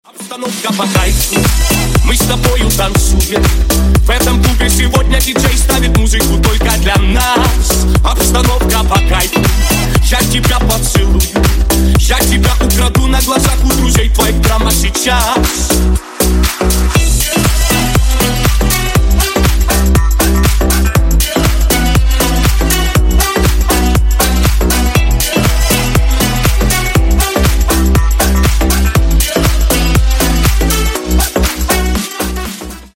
Клубные Рингтоны
Рингтоны Ремиксы
Танцевальные Рингтоны